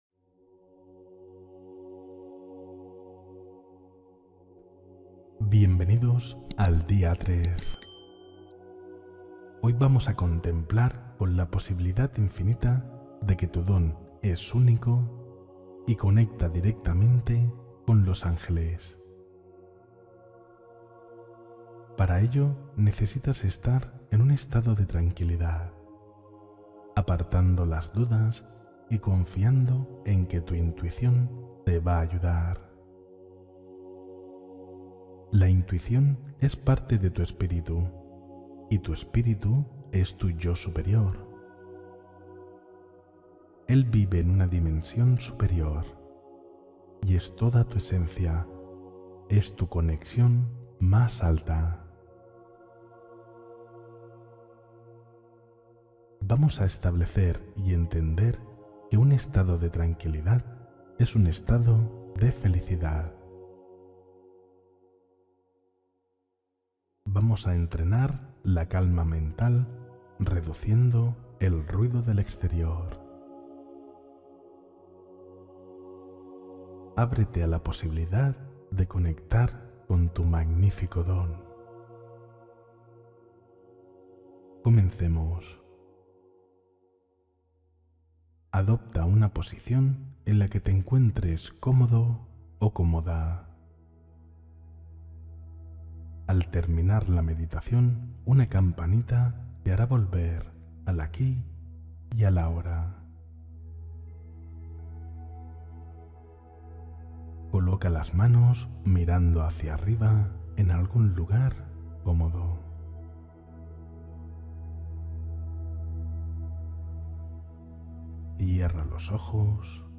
Mensaje Interior Angelical: Meditación de Escucha y Claridad